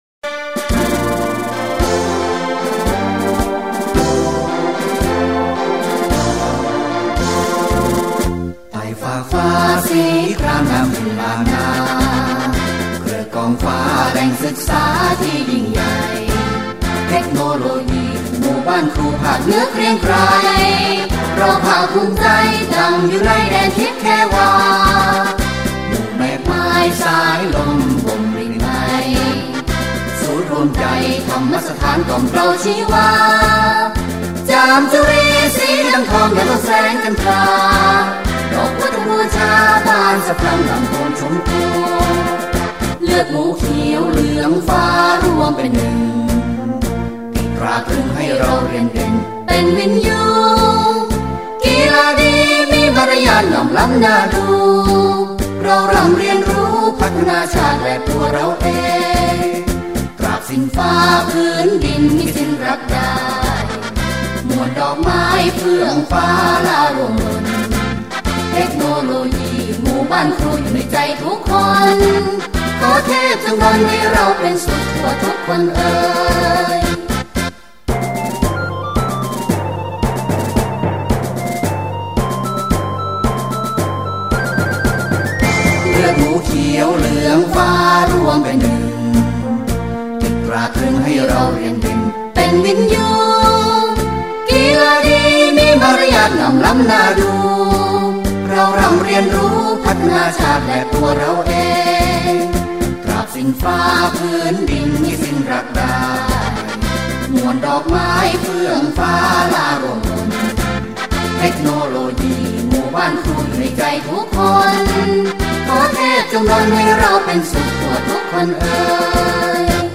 เพลงมาร์ชวิทยาลัย